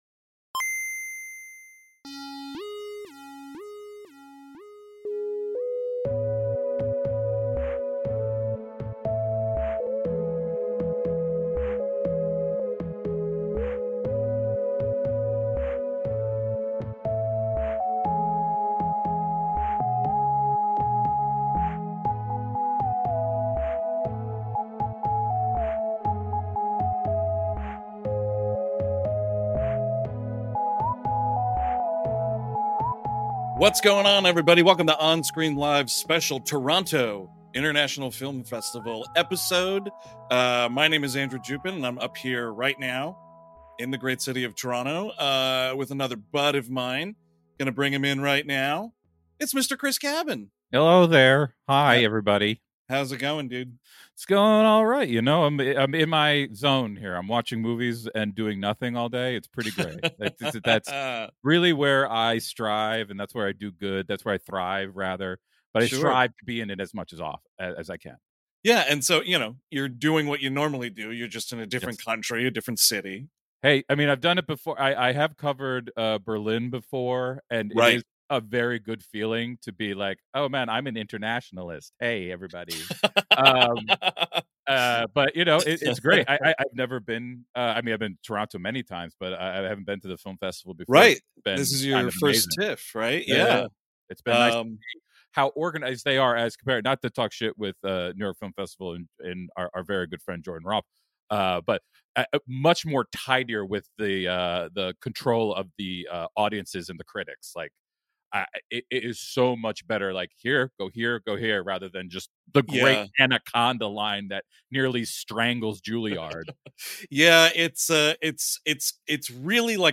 On this special edition of On-Screen Live, we were live (thanks to the spotty hotel wi-fi holding out) from the 2024 Toronto International Film Festival!